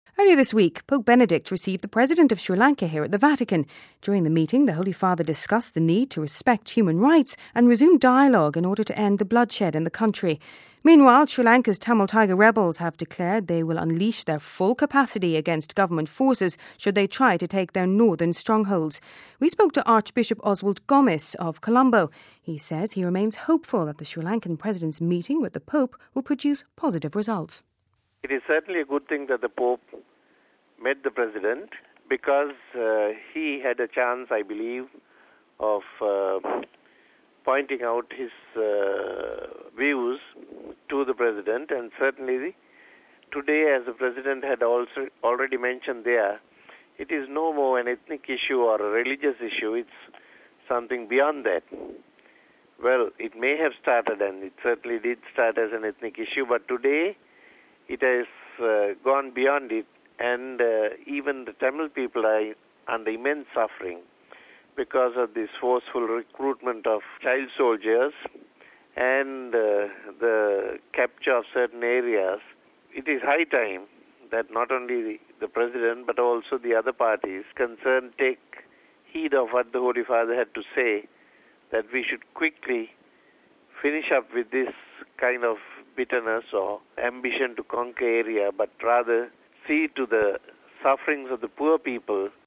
We spoke to Archbishop of Columbo, Oswald Gomis. He says he hopes the meeting will bring positive results...